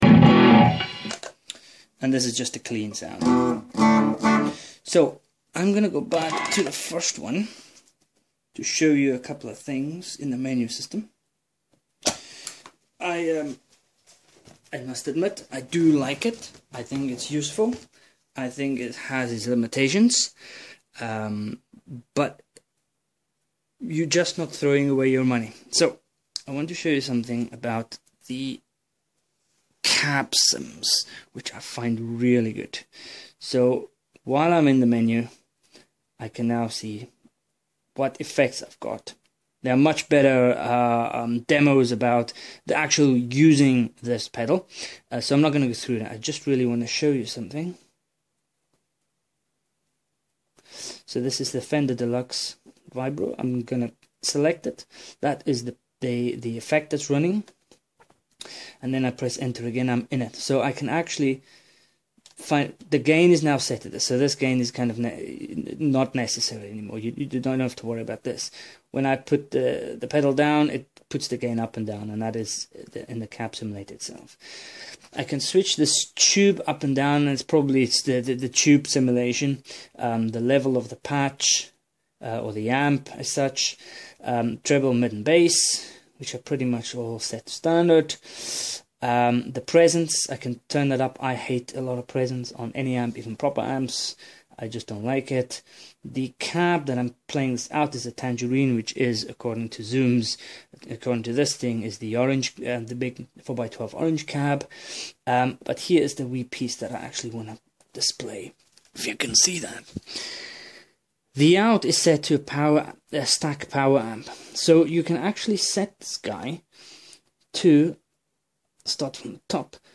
ZOOM G1Xon Demo part 2
Zoom g1xon is a digital multi effects unti from zoom and includes a 30 second looper and some great amp simulators